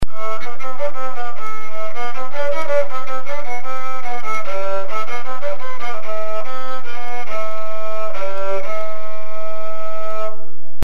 REBEC
Rebec Sound Clips
It is played with a simple horsehair bow and makes a distinctive rasping sound.